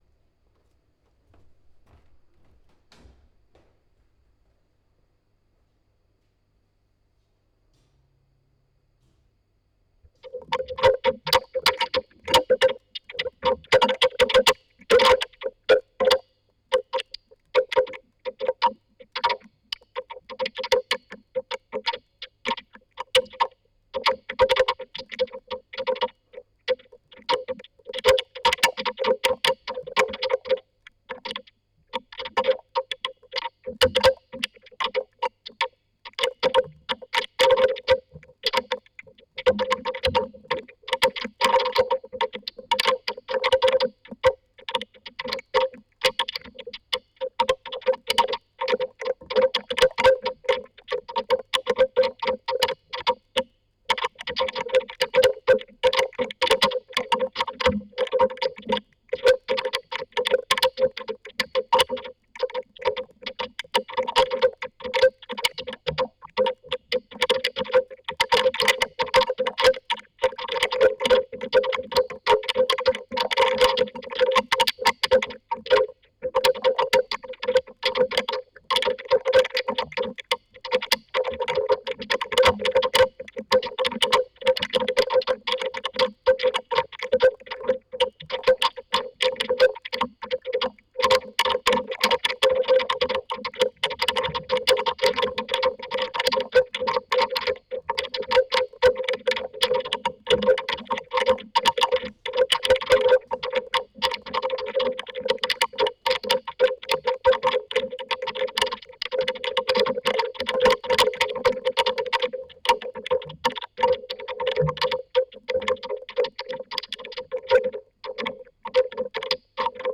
Links: Holzboden (Synthese)
Rechts: Fell (Analyse)
HP Envy Laptop, Audacity, Miniklinke auf Chinch, Klinke Adapter, Mackie 1202VLZ pro Mixer, Insert out in MAM VF11 Vocoder Analyse + Synthese
Vocoder out in Mackie 1202VLZ pro Mixer, symmetrisch in Zoom H6, asymetrisch in Amp Fender Princeton Chorus mit 2 in 1 Adapter